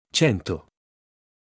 Cento (Italian pronunciation: [ˈtʃɛnto]
It-Cento.wav.mp3